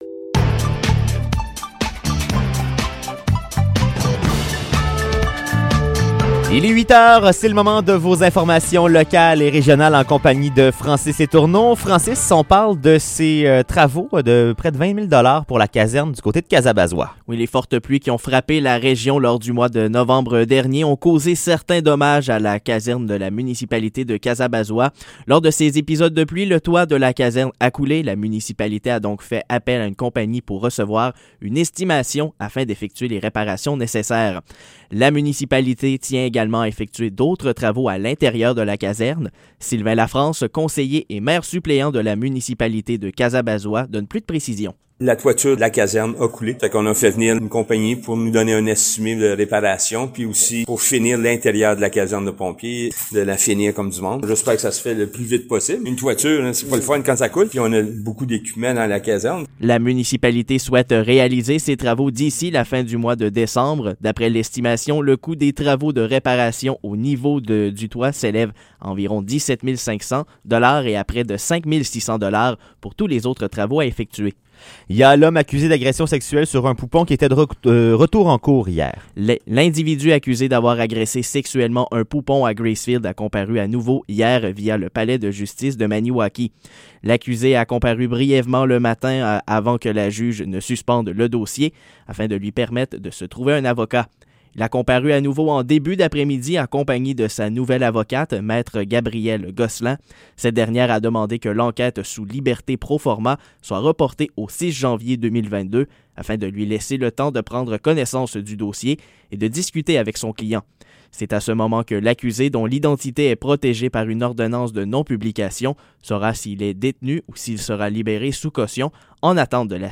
Épisode Nouvelles locales - 24 décembre 2021 - 8 h